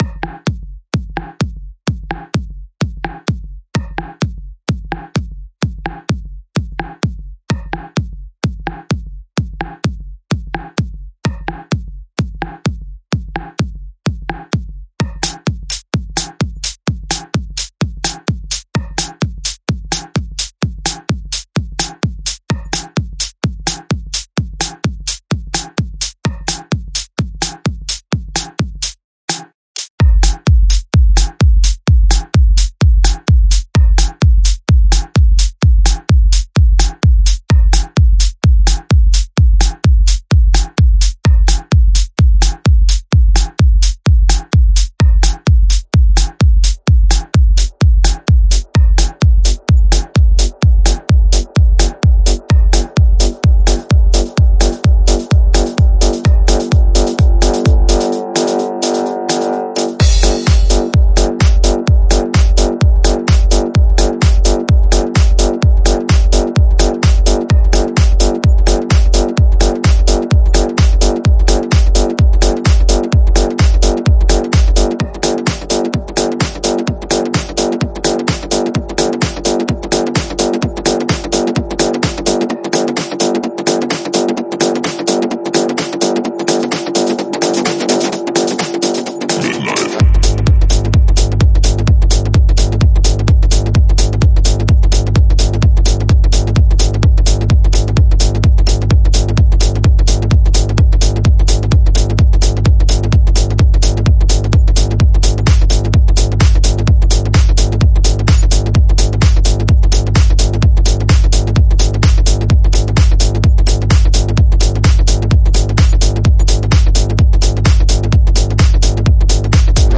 Techno EP